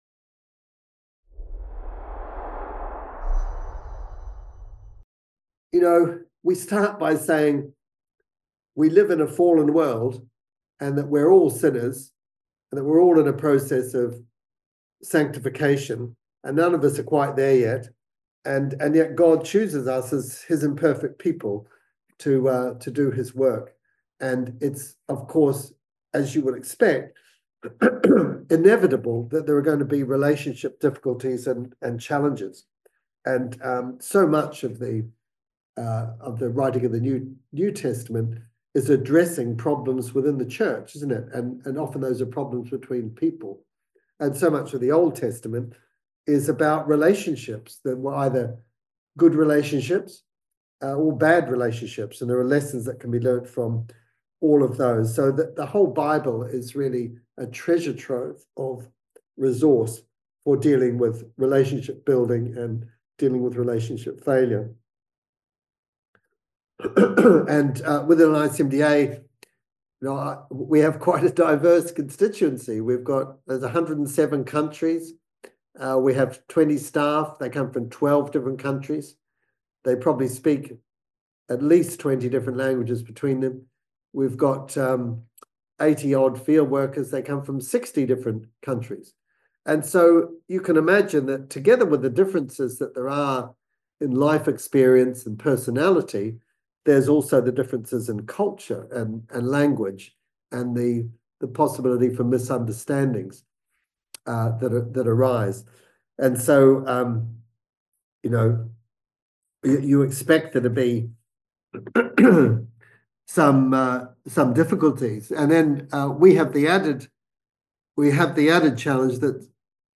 Event: Master Class Topic